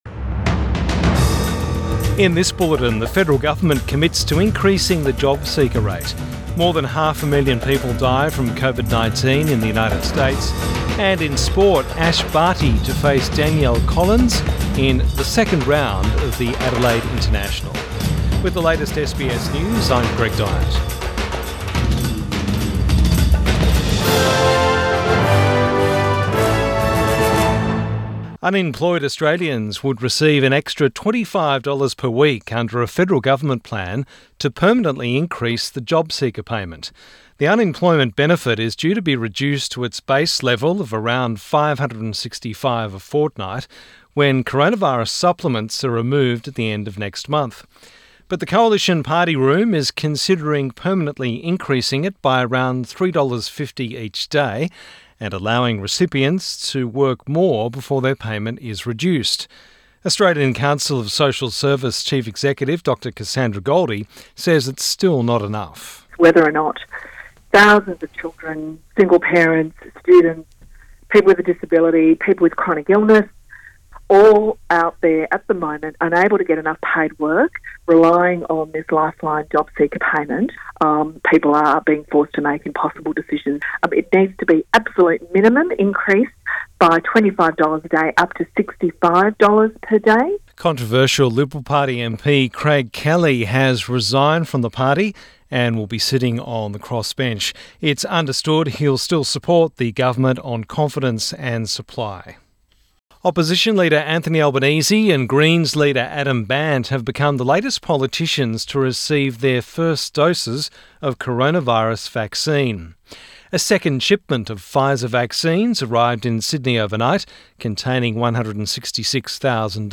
Midday bulletin 23 February 2021